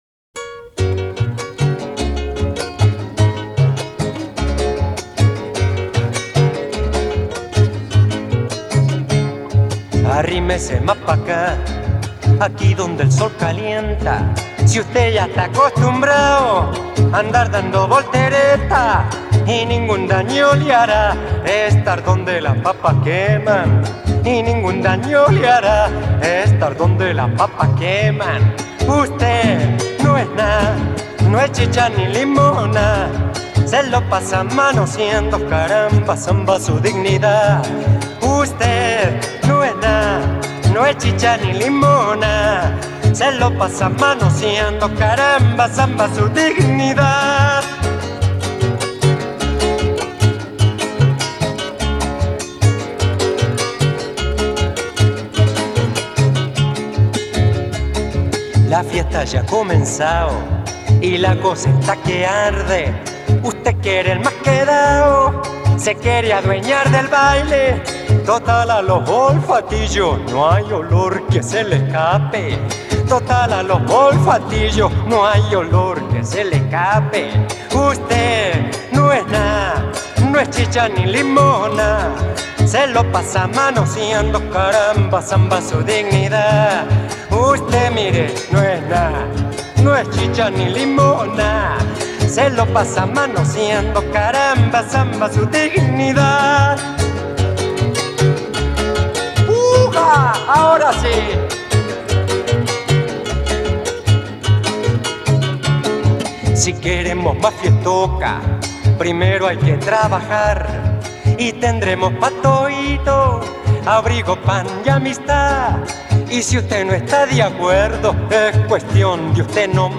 ESTILO: Cantautor